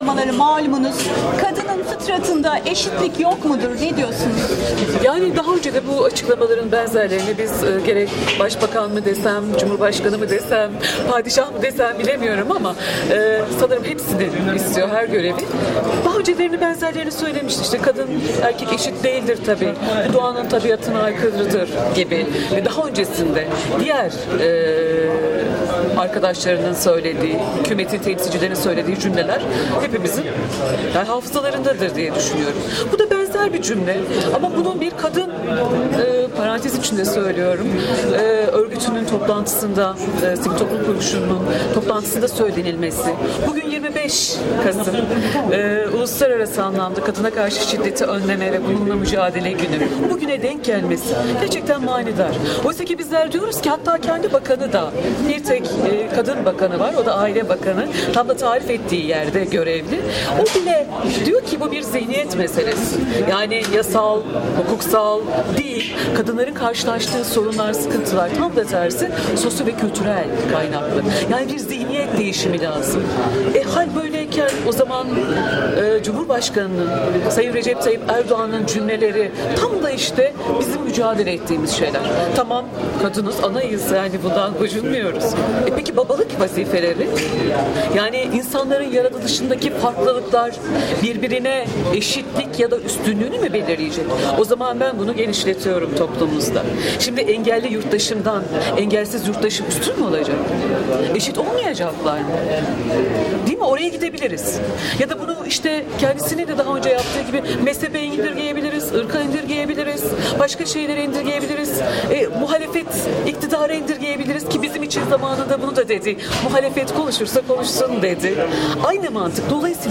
CHP Tekirdağ Milletvekili Candan Yüceer